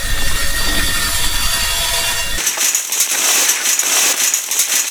wire_cut.ogg